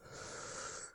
imvest-stress-test-main-quest/Inhale.wav at master
Inhale.wav